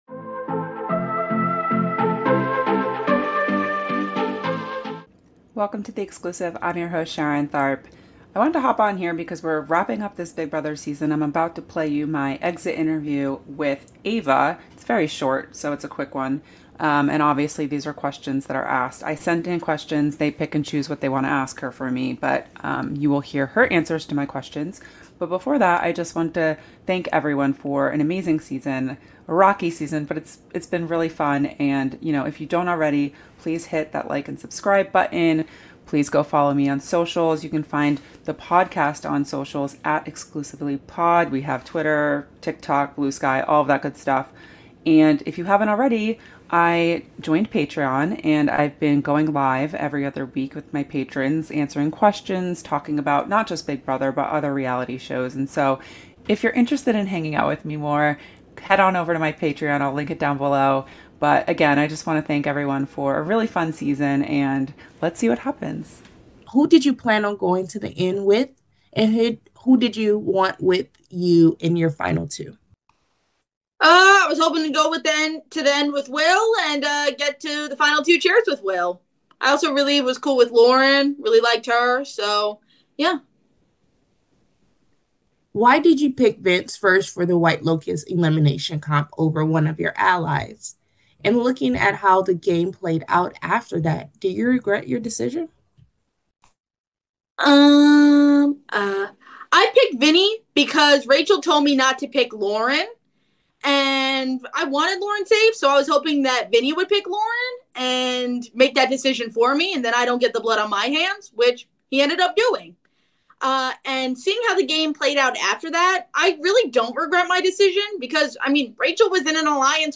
Big Brother 27 Exit Interview